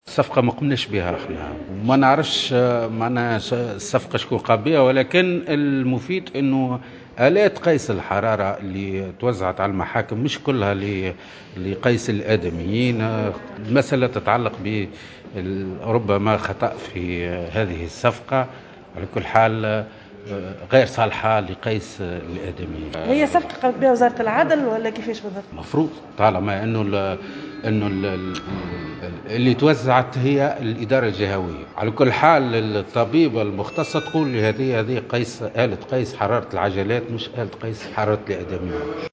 تصريح
على هامش جلسة للاستماع له اليوم، صلب لجنة التشريع العام